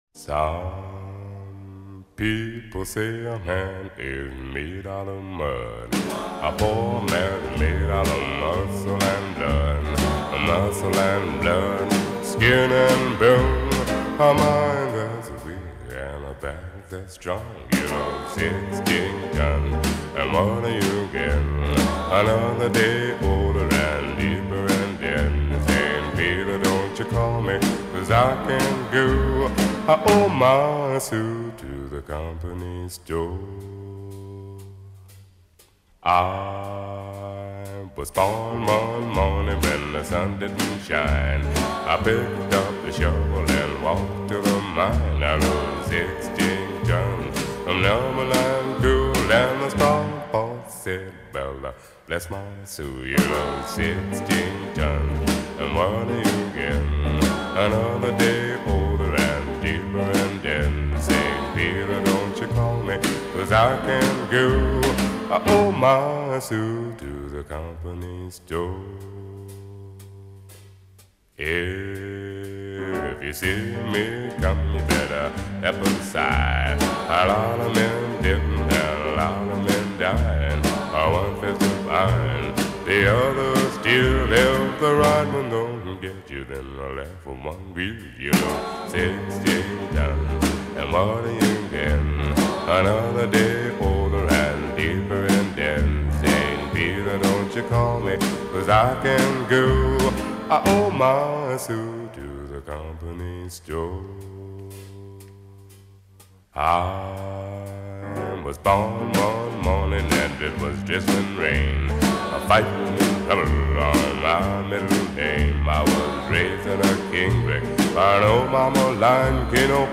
• Качество: 235, Stereo
Blues
jazz
Стиль: Blues, Jazz